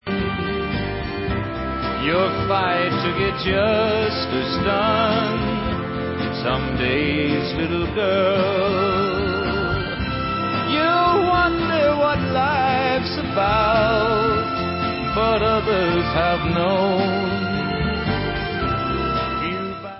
Western soundtracks